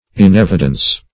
Meaning of inevidence. inevidence synonyms, pronunciation, spelling and more from Free Dictionary.